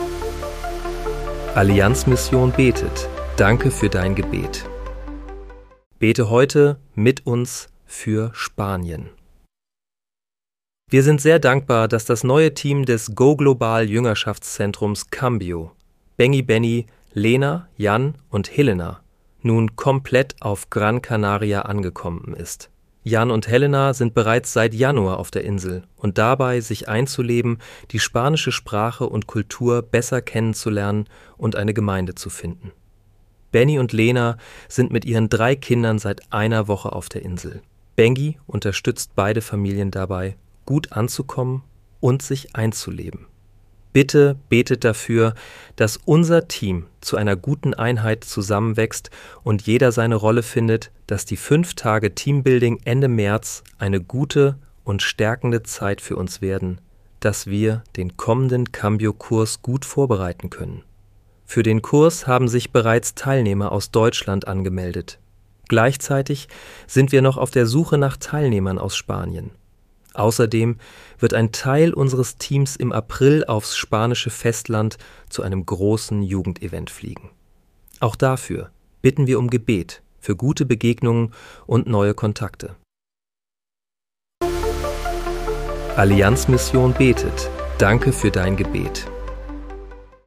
Bete am 18. März 2026 mit uns für Spanien. (KI-generiert mit der